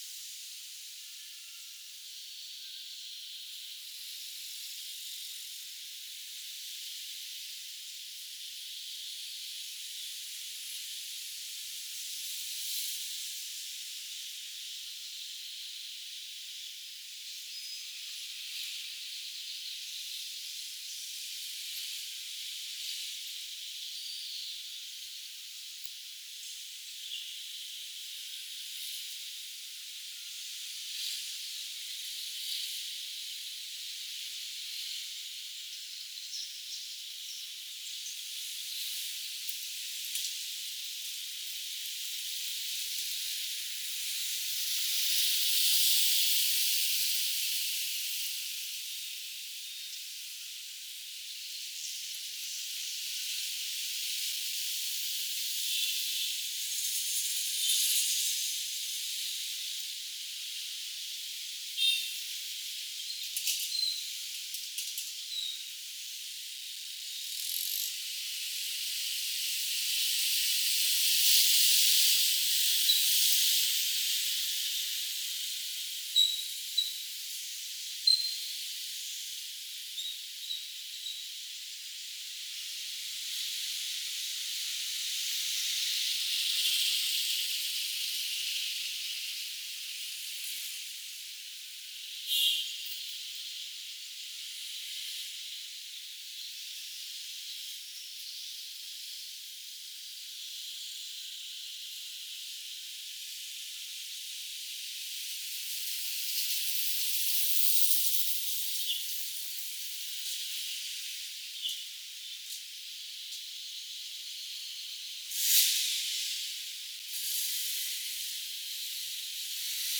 Demonstration soundscapes
61580 | biophony 61575 | anthropophony